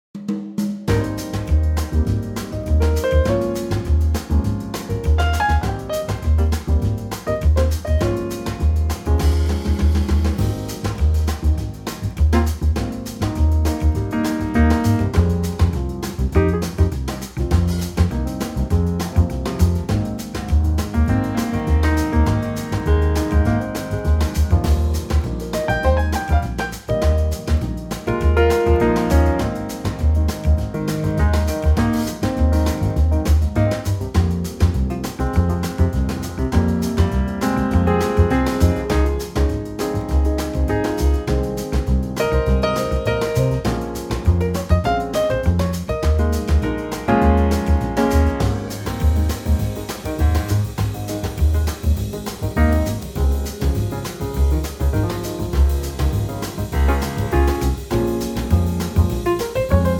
Unique Backing Tracks
key - Ab - vocal range - Bb to Eb